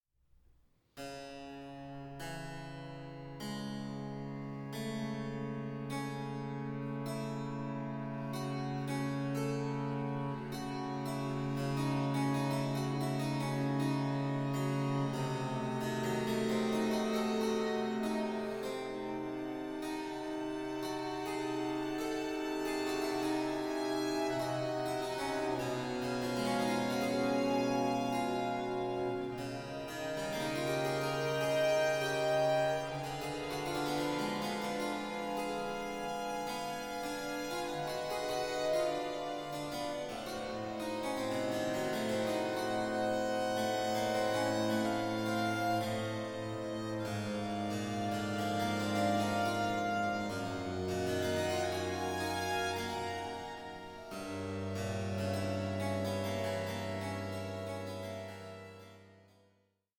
Recorder player
the recorder in place of the violin